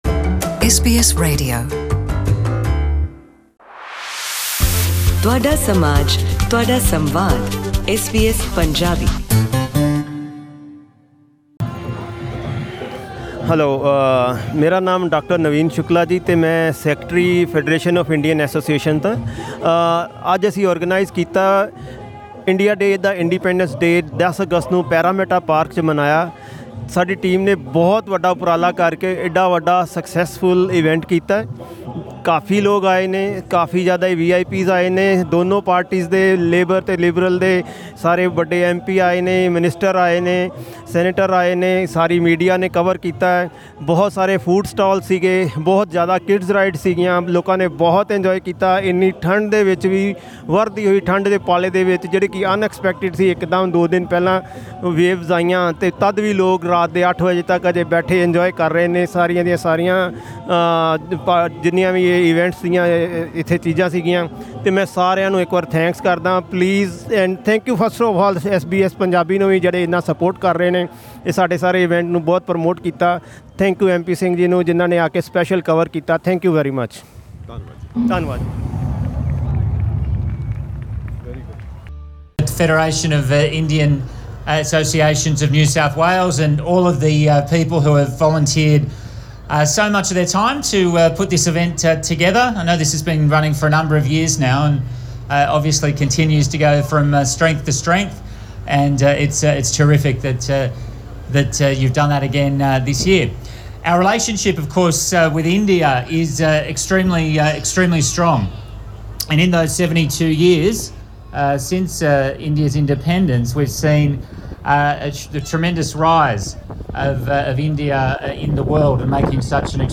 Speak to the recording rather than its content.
Click on the audio link above to hear some interviews recorded on the day, and more glimpses of the event can be viewed on SBS Punjabi's Facebook page